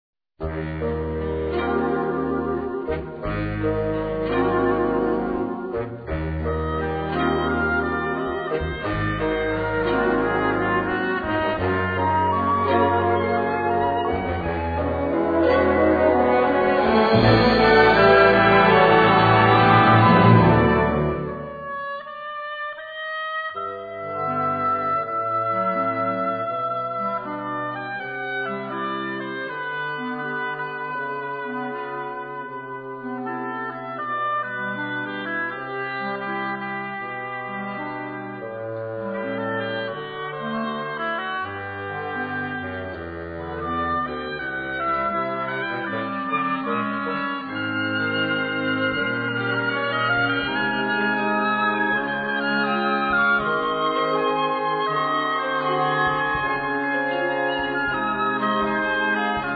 Gattung: Medley
Besetzung: Blasorchester
Im Big-Band-Sound.